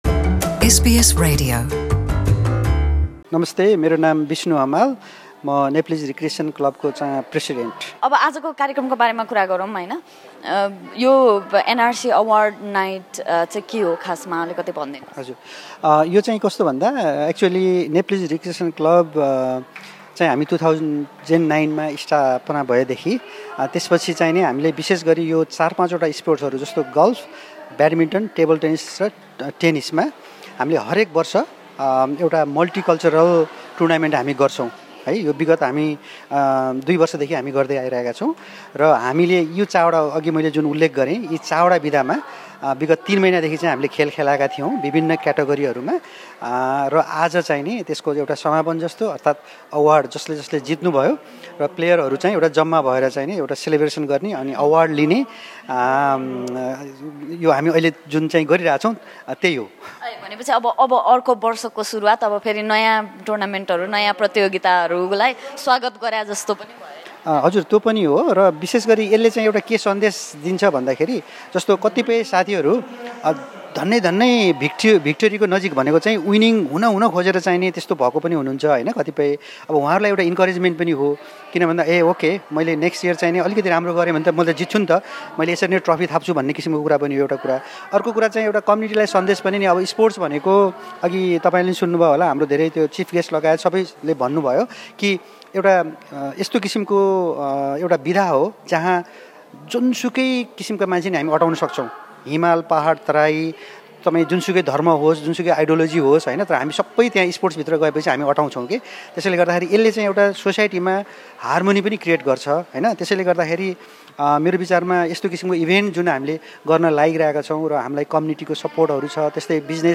एसबीएस नेपाली सँग गरेको कुराकानी